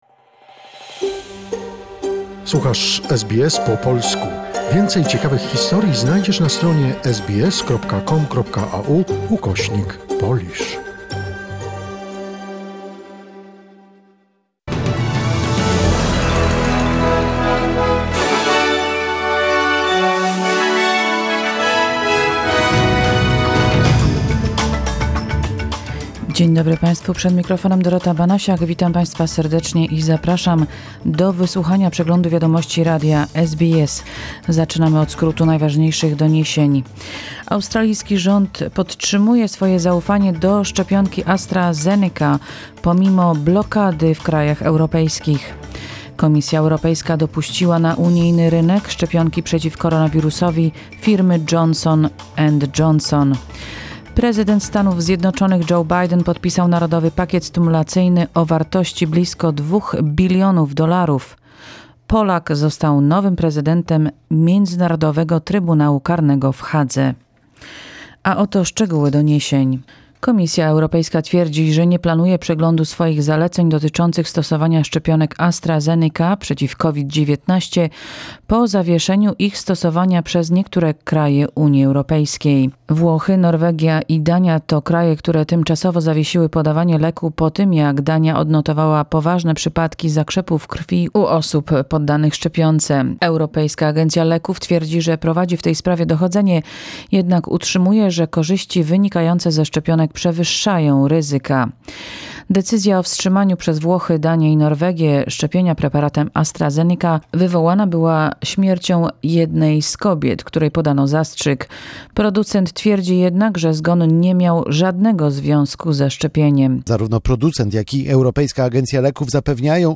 Wiadomości SBS, 12 marca 2021r.